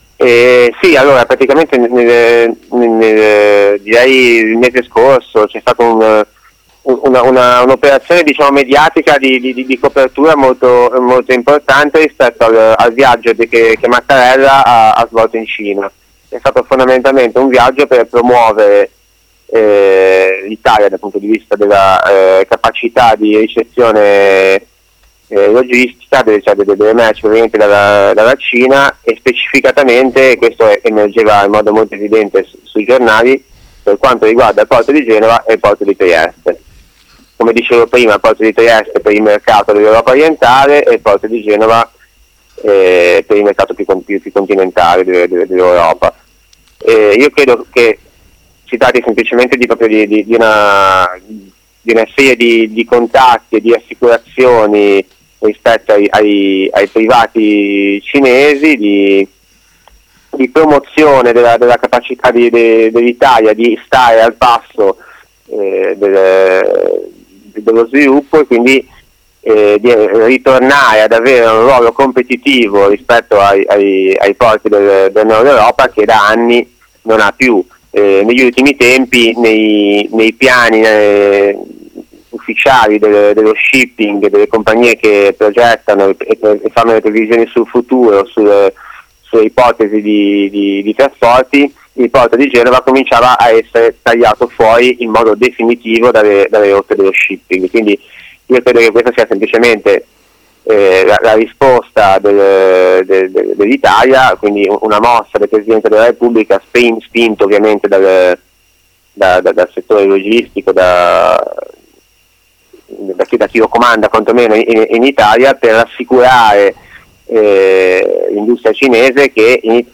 abbiamo proseguito la chiacchierata ampliando lo sguardo ai vari porti europei, che competono tra loro per accaparrarsi i flussi di merci e allo stesso tempo corrispondono a diversi punti di entrata dei conteiner per le varie zone dell’europa continentale